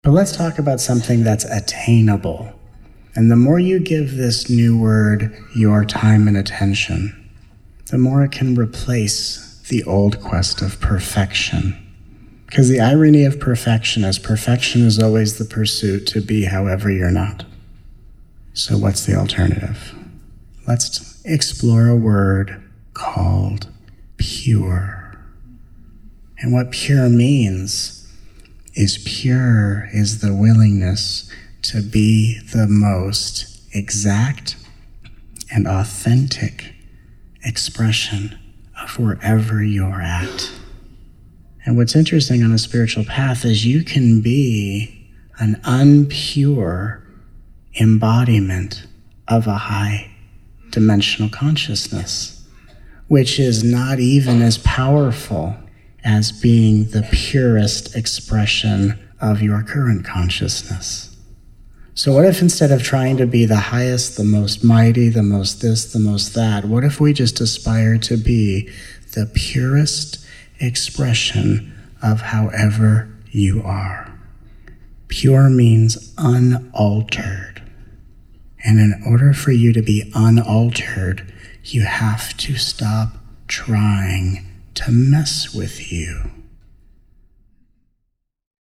Please join us for these 17+ hours of nourishing, uplifting, often hilarious, peaceful, and powerful teachings from this miraculous 5-day Soul Convergence.